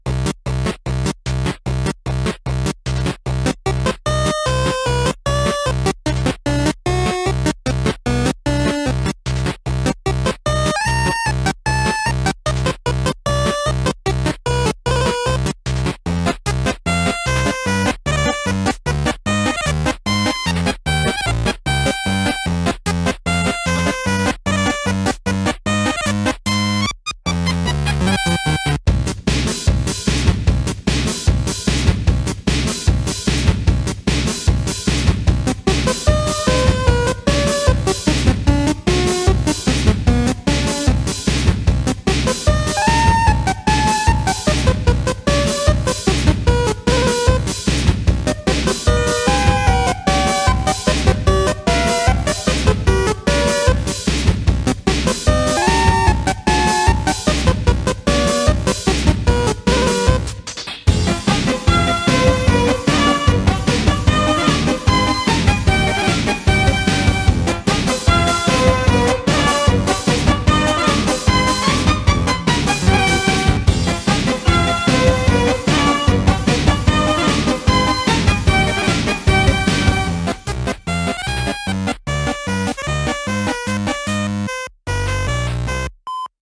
BPM150
Audio QualityCut From Video